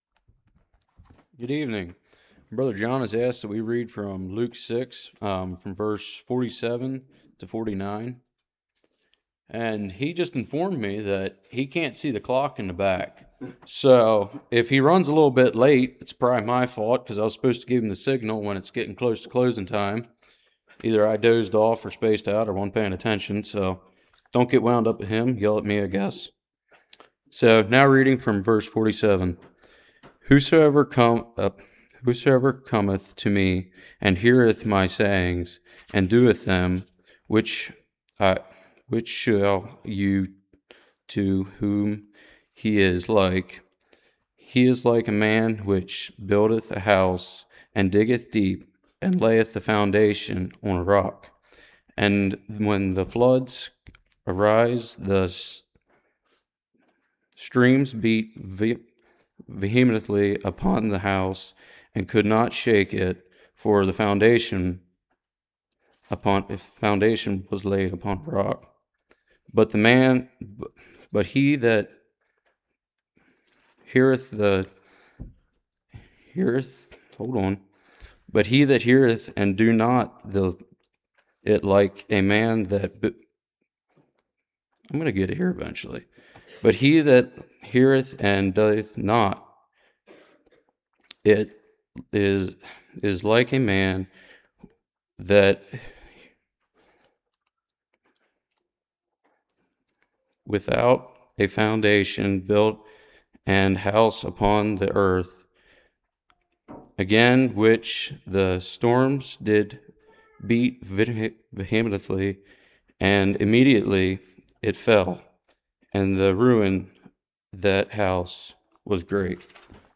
Passage: Luke 6:47-49 Service Type: Revival